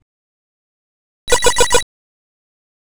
Possibly an odd one, but does anyone have a .WAV or .MP3 of the startup sound a PET (& the PC5 & PC10 made the same one) ?
pet-sound.aiff